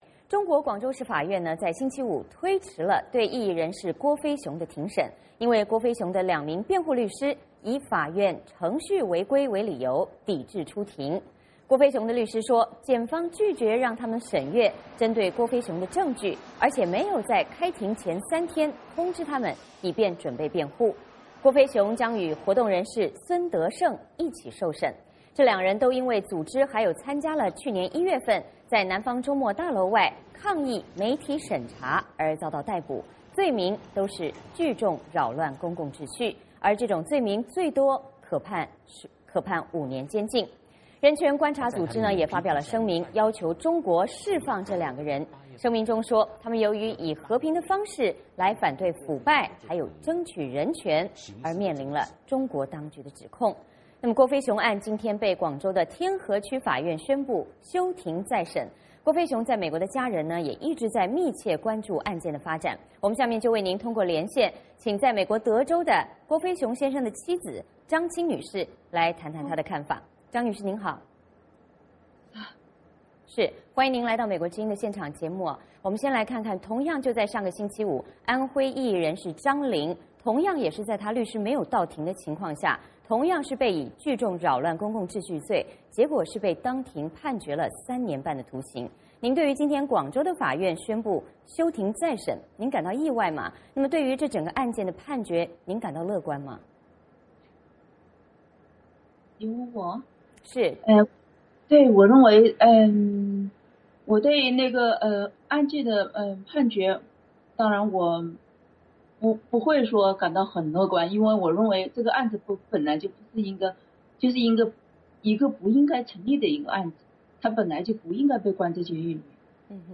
VOA连线：郭飞雄案开庭，律师未到随即休庭